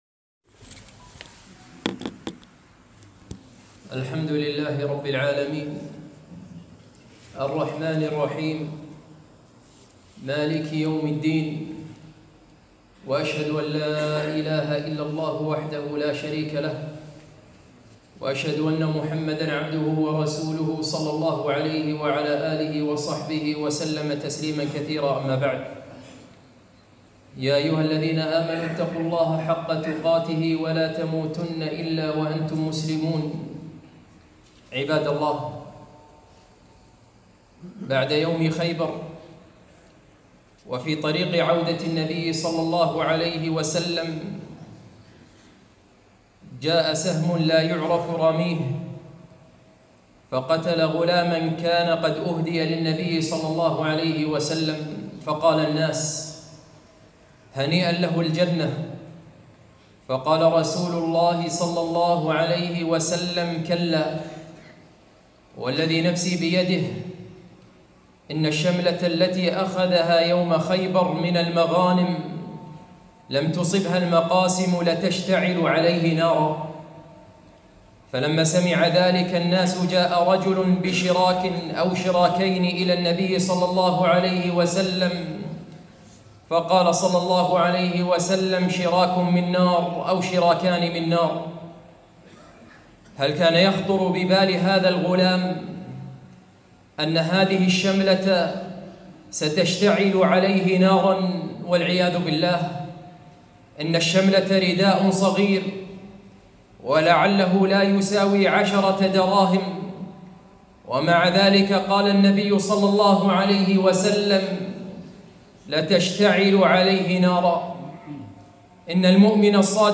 خطبة - أنا وأهل بيتي ومحقرات الذنوب